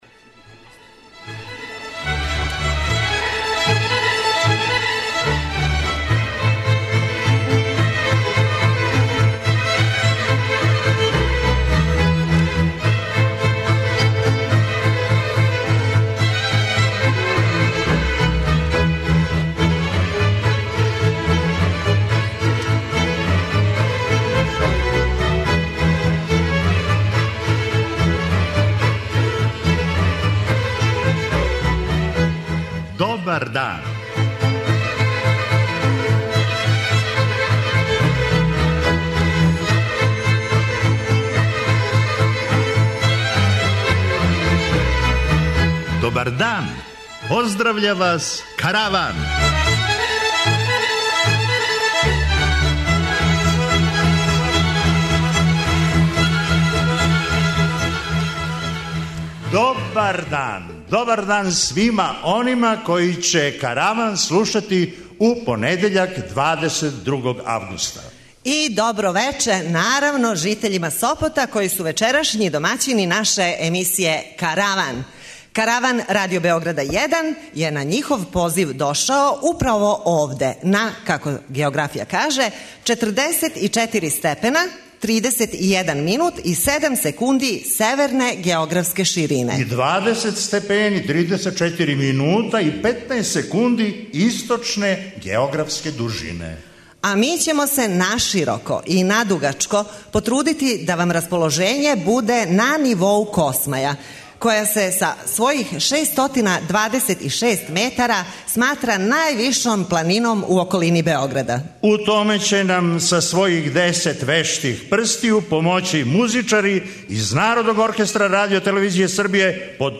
Овога пута житељи Сопота имали су прилику да буду део јединственог и непоновљивог догађаја - присуствовали су јавном снимању Каравана, а ви сте данас у прилици да чујете управо тај сопотски Караван!
преузми : 23.34 MB Караван Autor: Забавна редакција Радио Бeограда 1 Караван се креће ка својој дестинацији већ више од 50 година, увек добро натоварен актуелним хумором и изворним народним песмама.